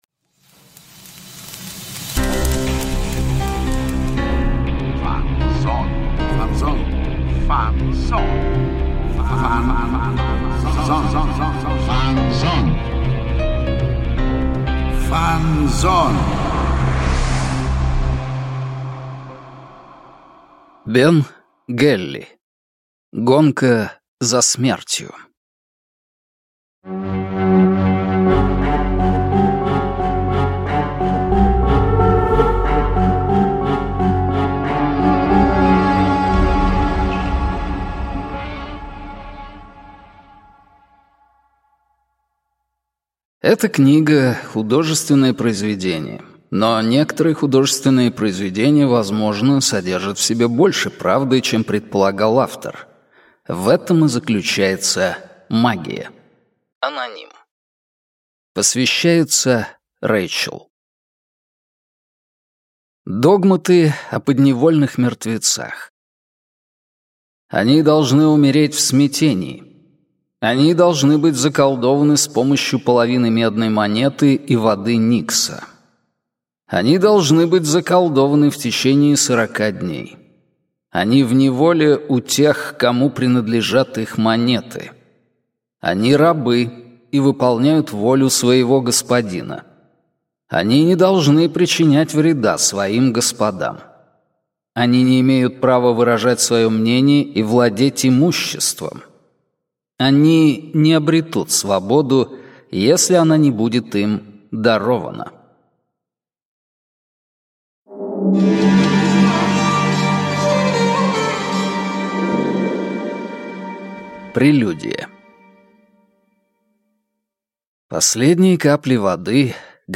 Аудиокнига Гонка за смертью | Библиотека аудиокниг